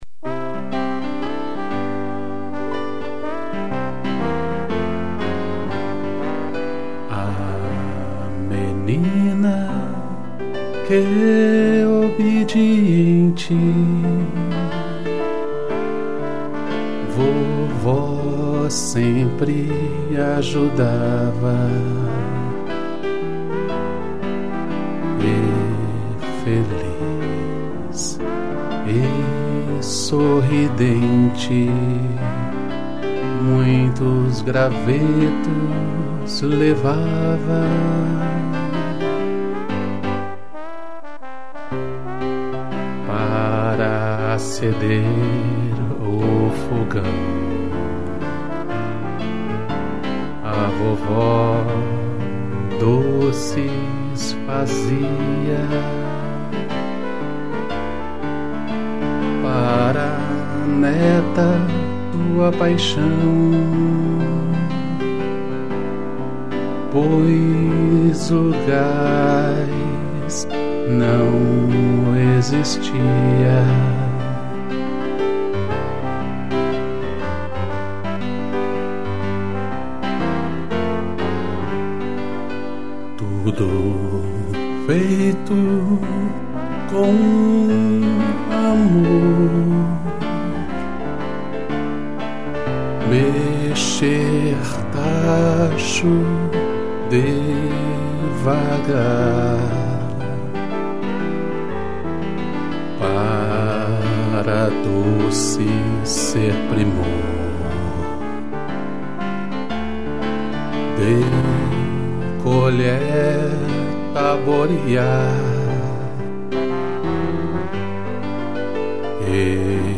2 pianos e trombone